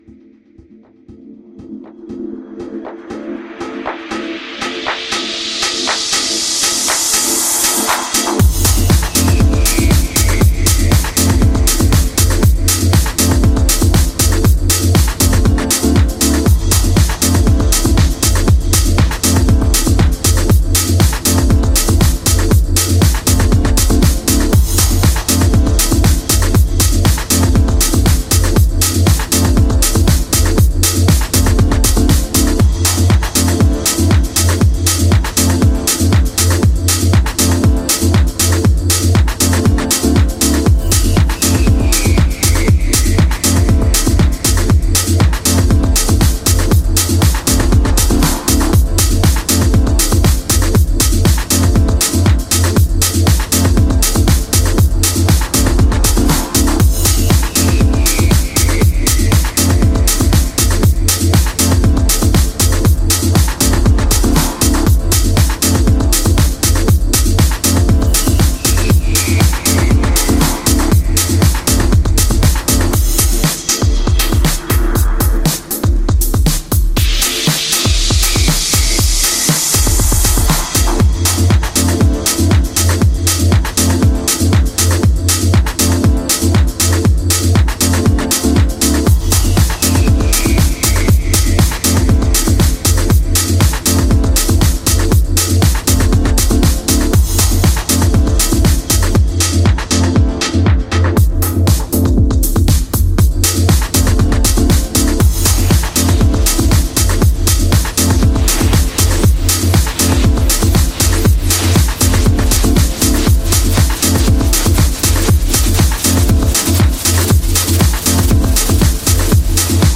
Музыка для показа мод